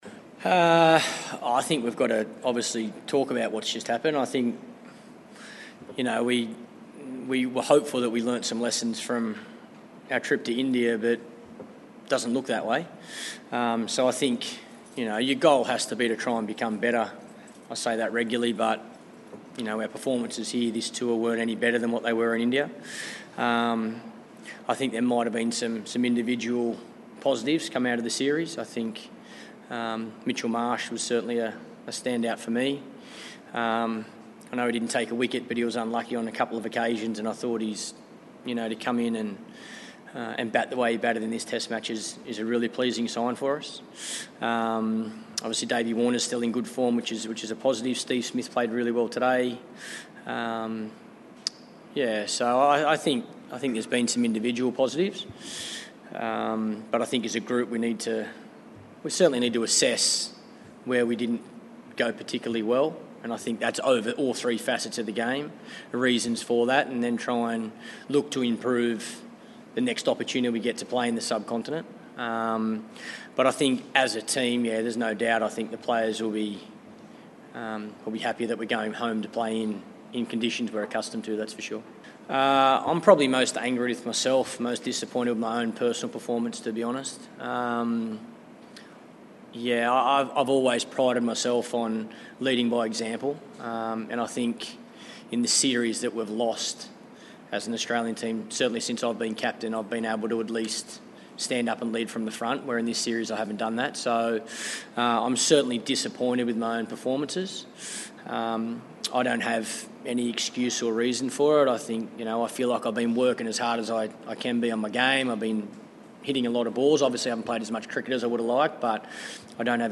Michael Clarke, the Australia captain, media conference, 3 November